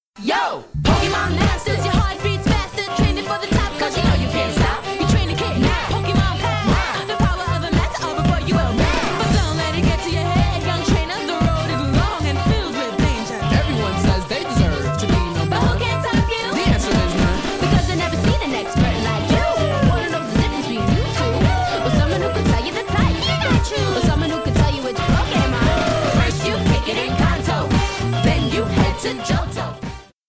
Ending Theme Song (Short Version)